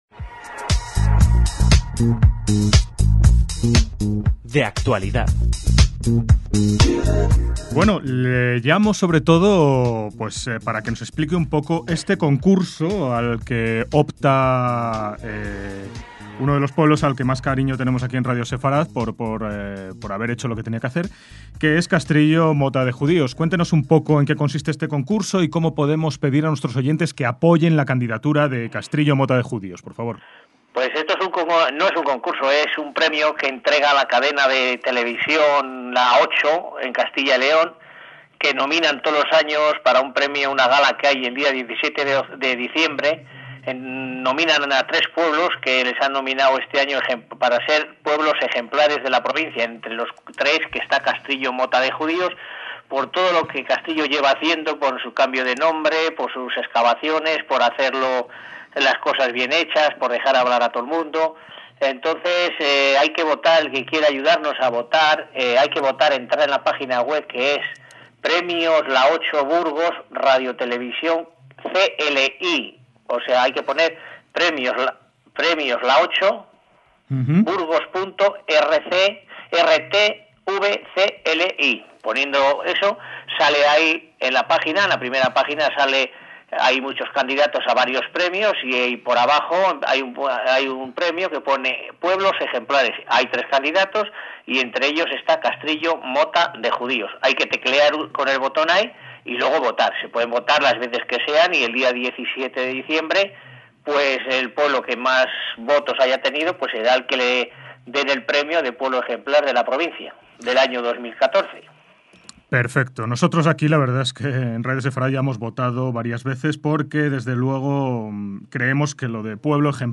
DE ACTUALIDAD - Entrevistamos a Lorenzo Rodríguez, alcalde de Castrillo Mota de Judíos, el pueblo burgalés que como ya saben nuestros oyentes recuperó este mismo año el que fue su nombre original. Castrillo Mota de Judíos ha sido seleccionado para optar a la distinción de Pueblo Ejemplar de la provincia, concedida por el Canal 8 de la TV de Castilla y León.